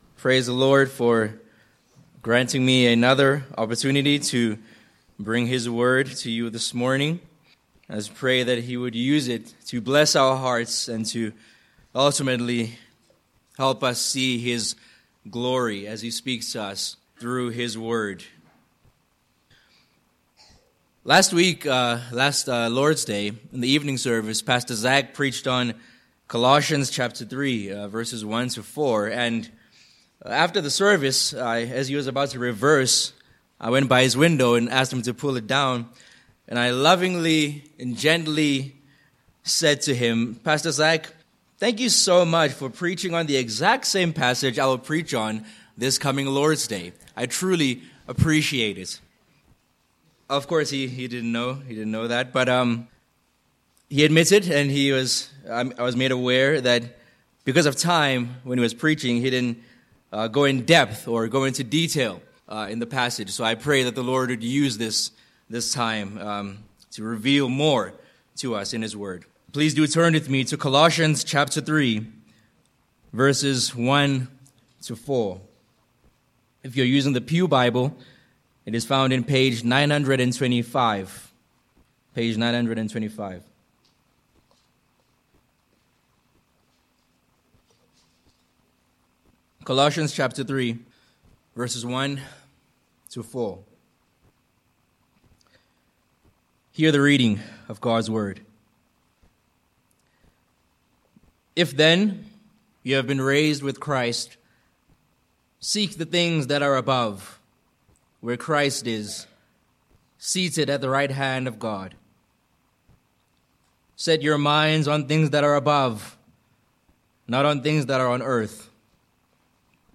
Sermon points: 1. Be Heavenly Minded (v1-2)